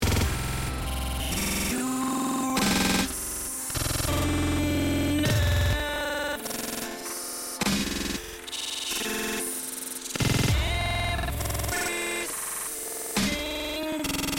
Das merken wir daran, dass mAirList gelegentlich “ins Stottern” gerät - Bedeutet, der Player läuft nicht mehr, auf dem Sender ist nur noch “ein Stottern” zu hören - Ein Neustart des PC’s ist dadurch erforderlich … Im Anhang ein Audiofile, wo das Problem zu hören ist.
Audio-Error.MP3 (225 KB)